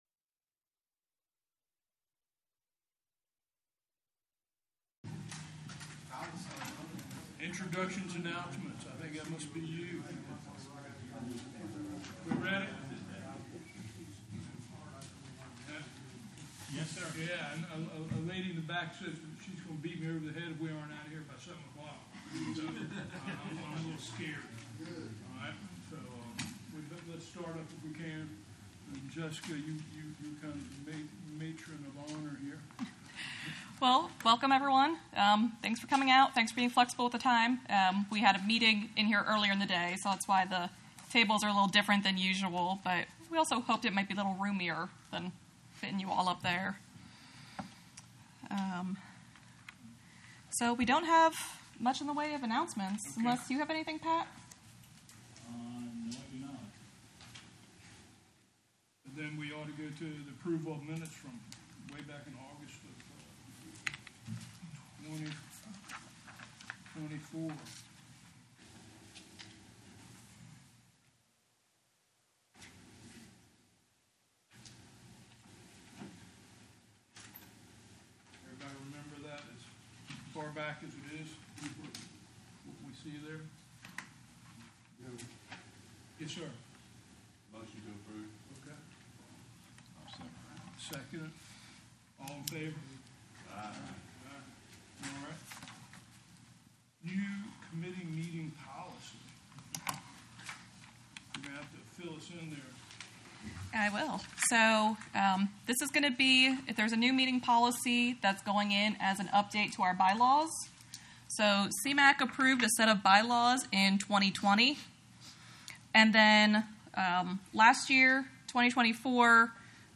VMRC - 08/26/2025, 9:30 AM: The August VMRC Commission Meeting will be held on Tuesday, August 26, 2025 at 9:30 AM at the VMRC Main Office. Please note, due to techincal difficulties, no livestream of the meeting is available.